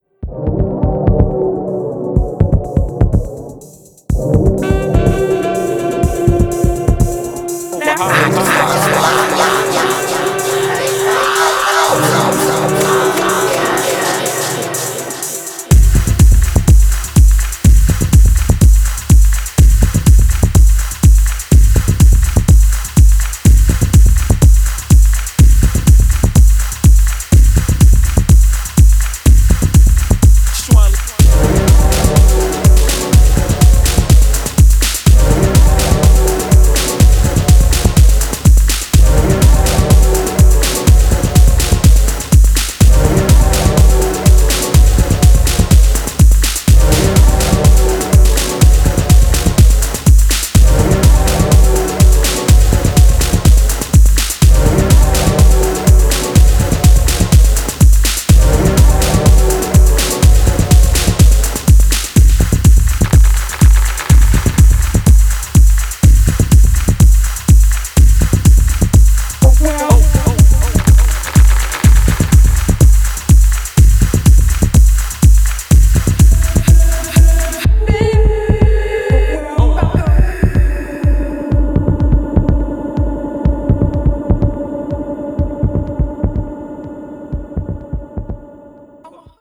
アフロ・オリエンテッドな要素とボトムヘヴィなリズムを組み合わせたダークでエネルギッシュなトラック群を展開しており
重厚なリズムワークの合間を抜けて覚醒的なホーンやヴォーカルが突如差し込まれる